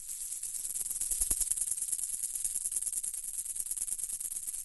Tiếng kêu Rắn Đuôi Chuông